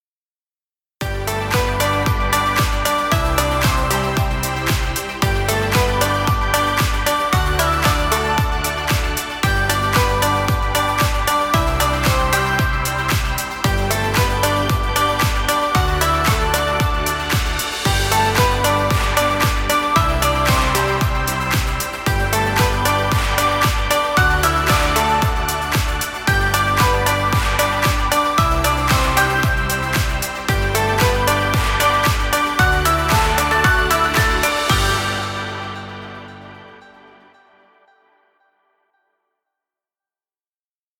Summer happy corporate music.